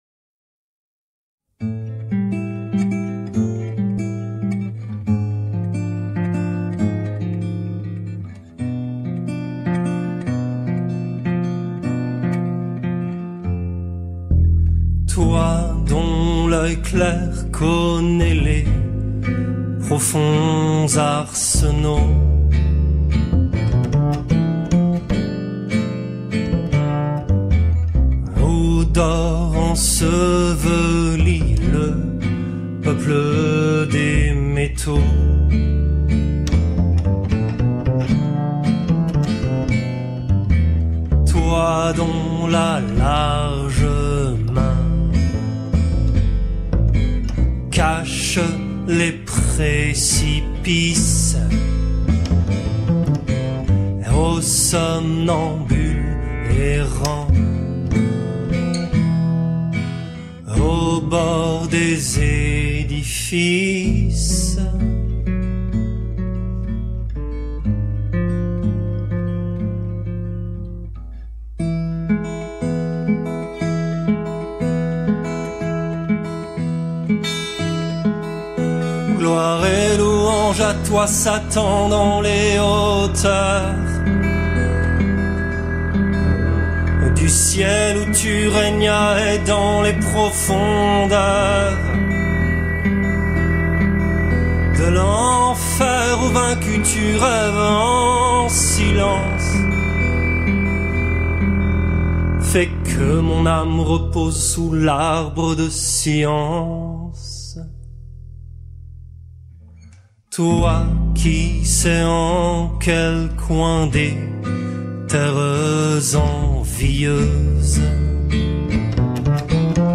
Chorale